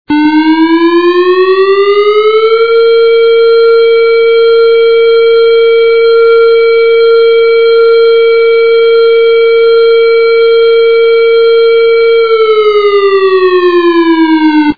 Minute and a half of the Alert tone (constant tone)
Here are several sound clips of a Whelen.
alert.mp3